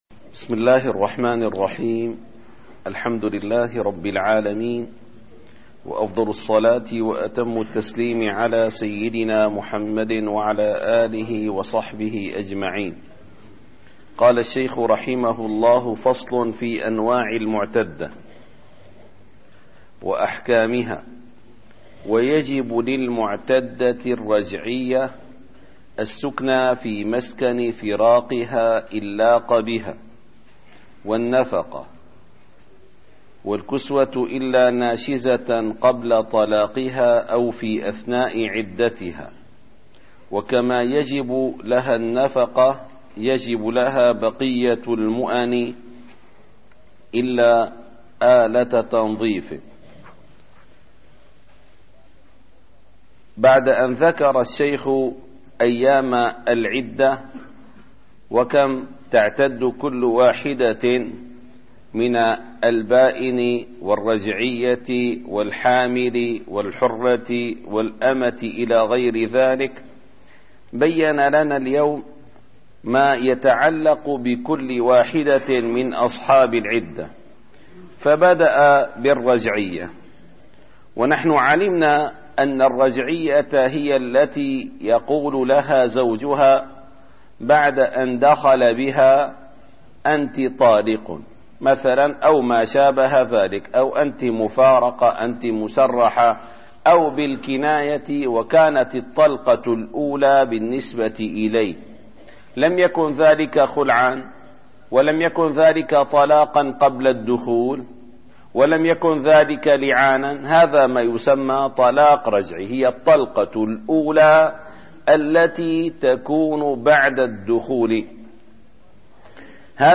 - الدروس العلمية - الفقه الشافعي - شرح ابن قاسم الغزي - فصل في أنواع المعتدة وأحكامها ص231